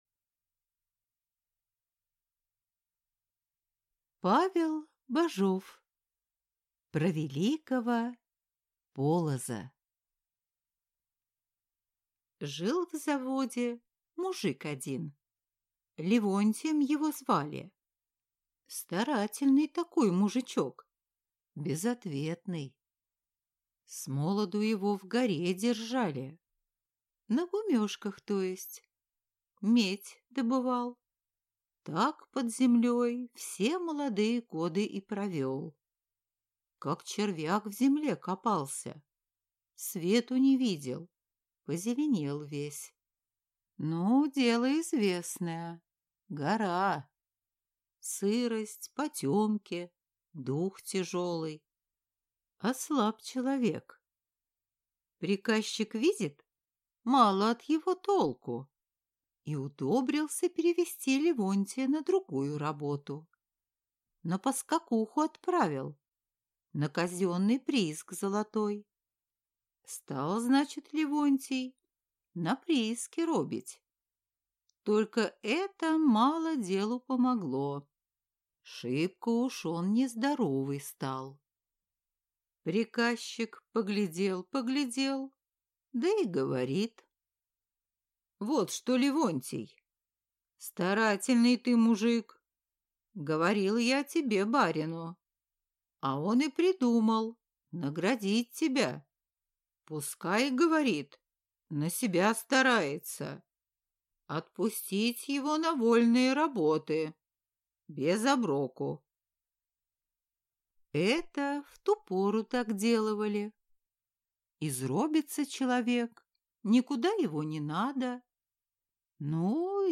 Аудиокнига Про Великого Полоза | Библиотека аудиокниг